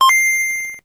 Gameboy & Gameboy Color Startup.wav